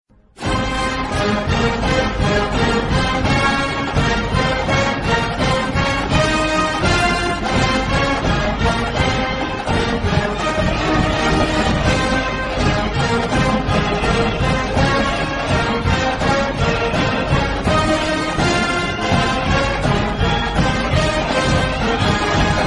Fight Song